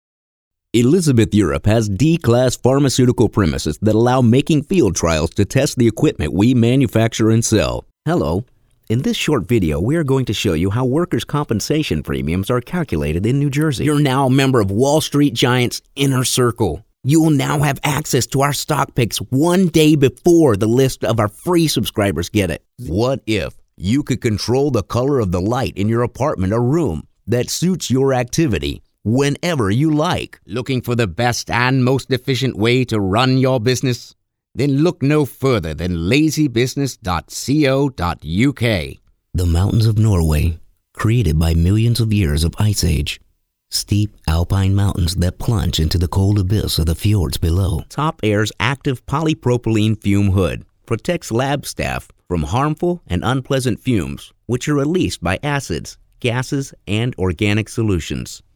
Adult (30-50) | Older Sound (50+)